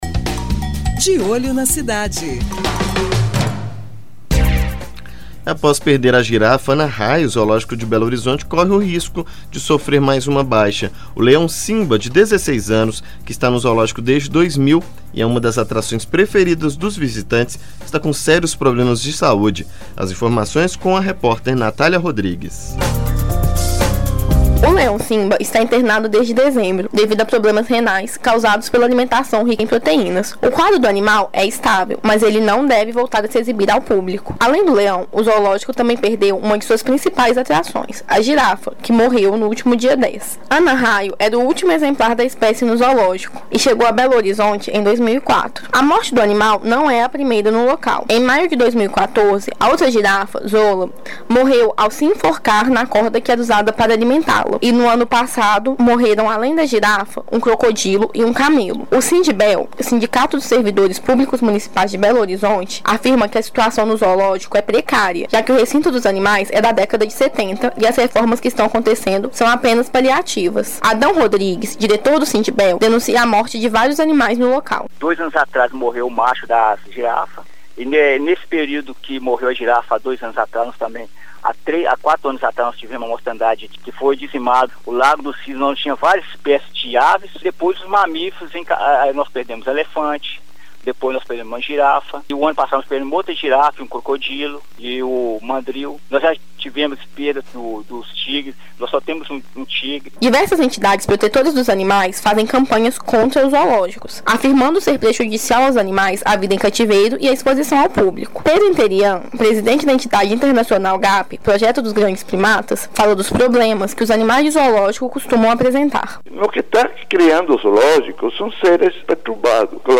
reportagem
entrevista